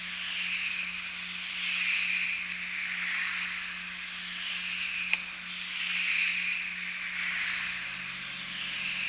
A study evaluating the potential of using acoustic monitoring for remote assessment of flying animal collisions at industrial wind energy facilities
Presumed flying animal collision sound - September 29, 2008 - #1